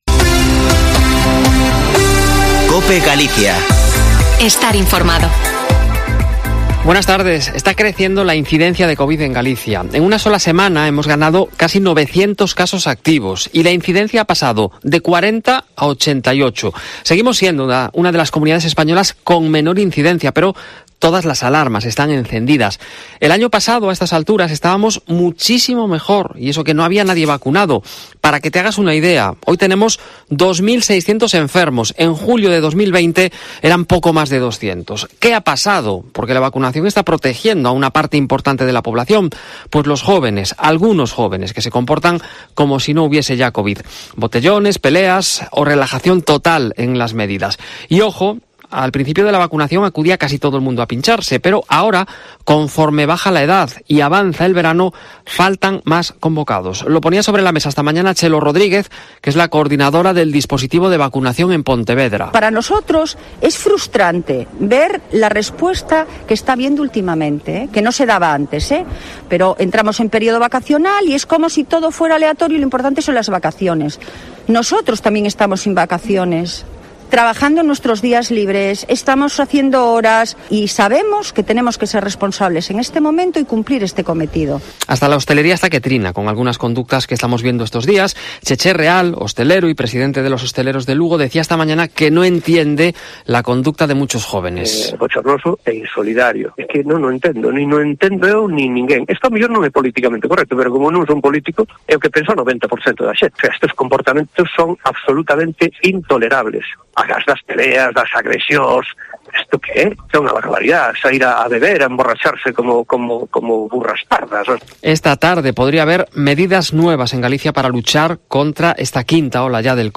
Informativo Mediodía en Cope Galicia 06/07/2021. De 14.48 a 14.58h